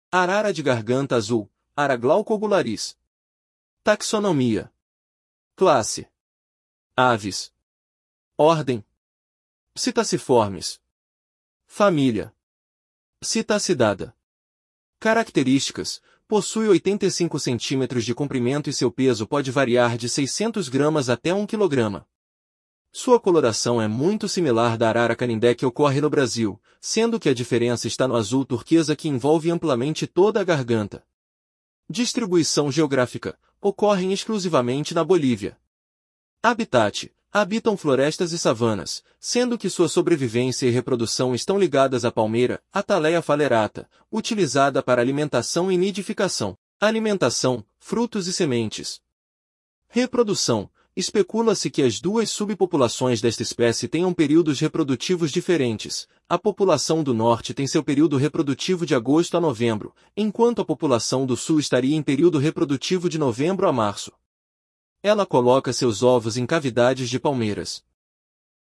Arara-de-garganta-azul (Ara glaucogularis)